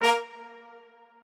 strings8_32.ogg